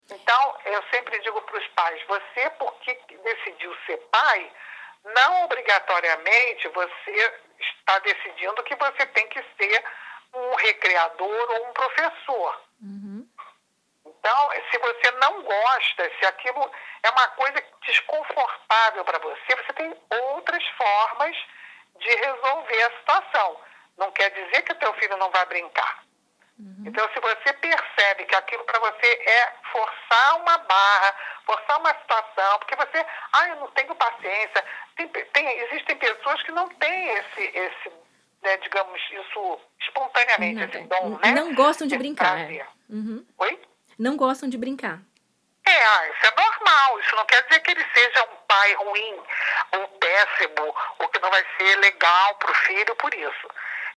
Ela esclarece melhor neste trecho da entrevista.